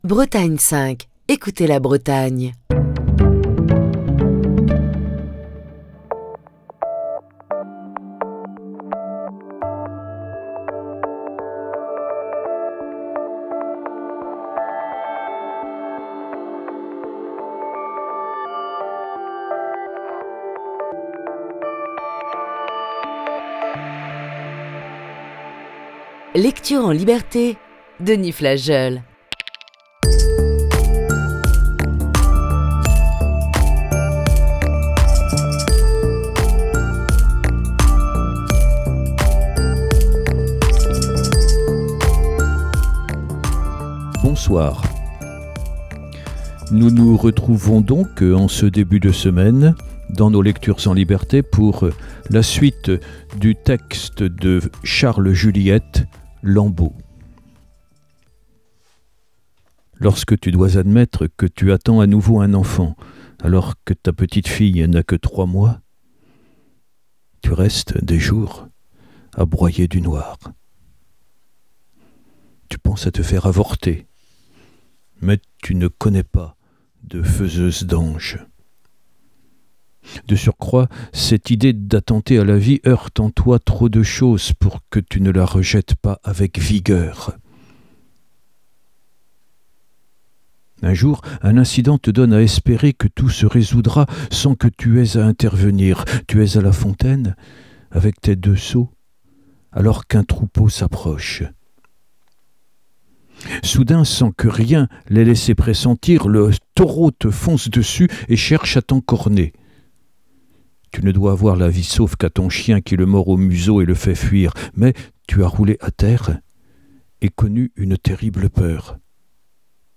Émission du 28 février 2022.